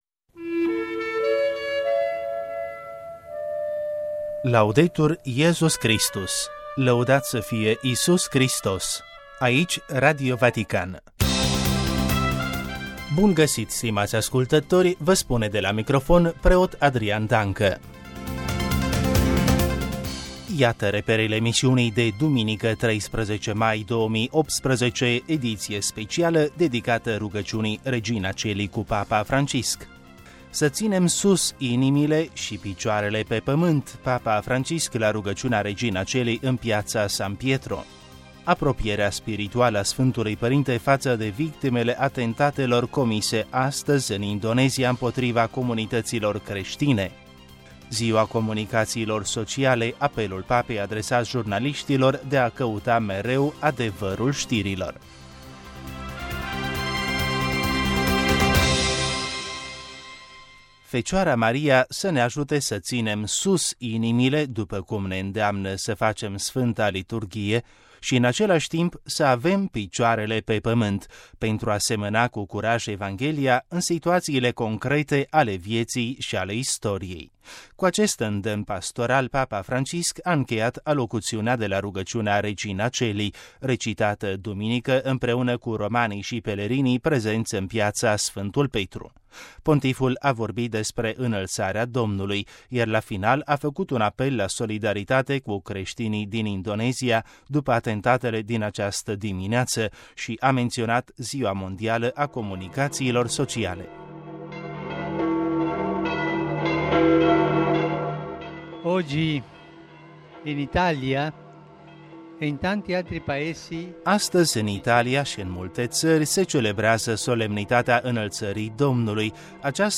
Cu acest îndemn pastoral, papa Francisc a încheiat alocuțiunea de la rugăciunea ”Regina Coeli”, recitată duminică, 13 mai 2018, împreună cu romanii și pelerinii din Piața Sfântul Petru. Pontiful a vorbit despre Înălțarea Domnului, iar la final a făcut un apel la solidaritate cu creștinii din Indonezia după atentatele din această dimineață și a menționat Ziua mondială a comunicațiilor sociale.
Primiți la final și Binecuvântarea apostolică invocată de papa Francisc la finalul rugăciunii ”Bucură-te, regina cerului”, binecuvântare ce ajunge prin mass-media la toți cei care o primesc în spirit de credință.